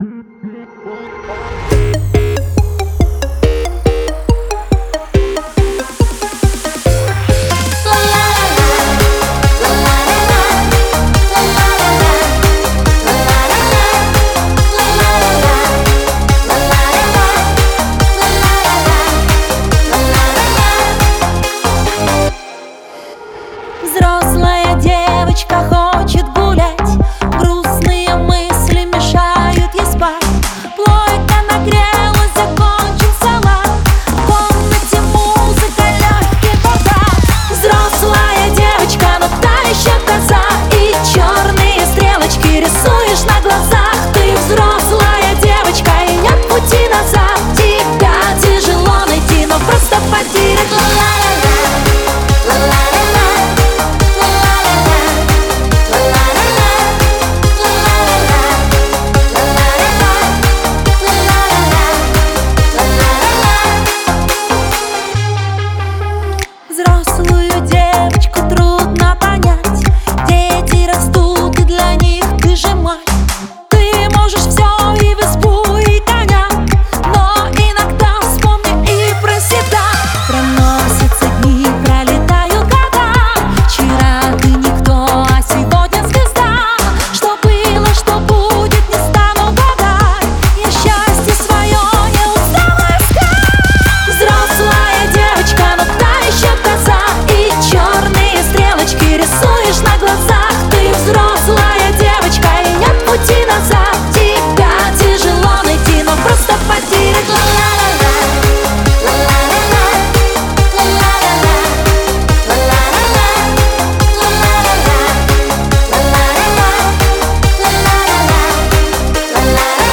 яркий поп-трек с элементами dance и электронной музыки